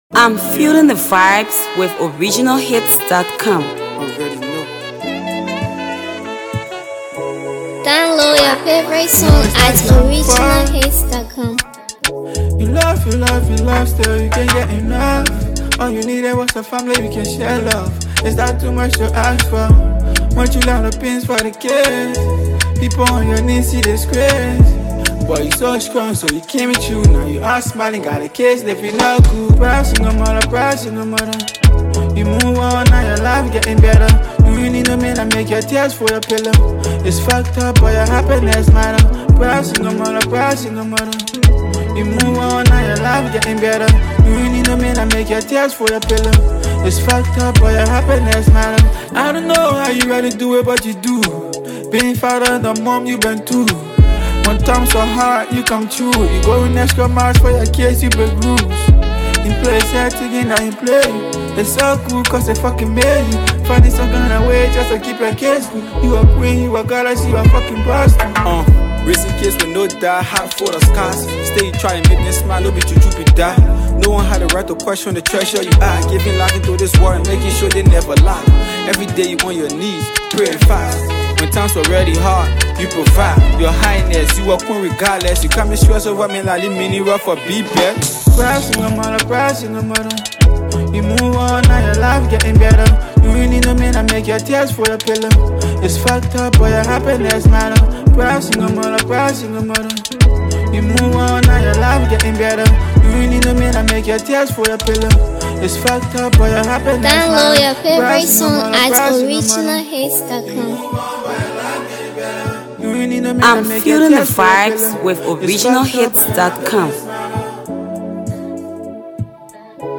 Versatile Liberian Hipco rapper
heart-touching banger
studio masterpiece